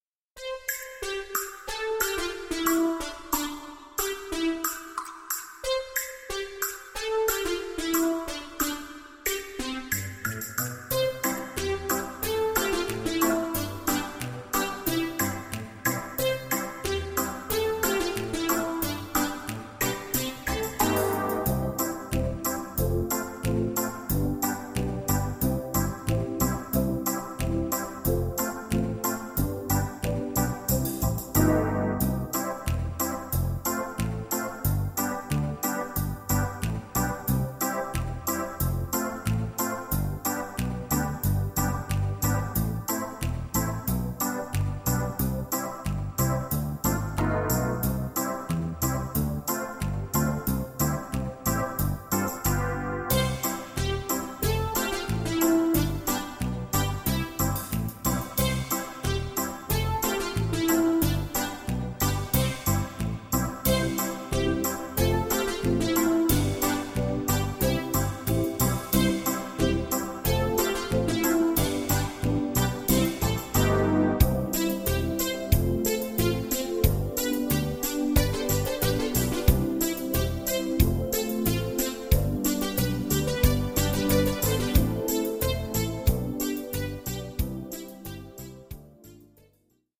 Das Playback-Album zur gleichnamigen Produktion.